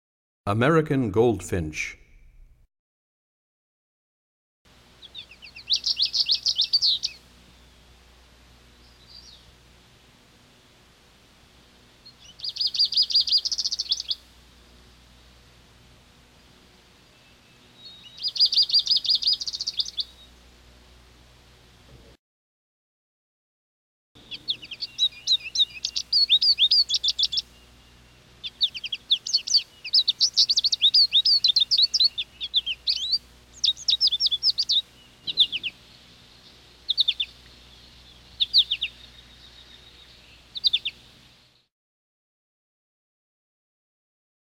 03 American Goldfinch.mp3